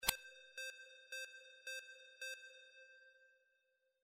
Stereo sound effect - Wav.16 bit/44.1 KHz and Mp3 128 Kbps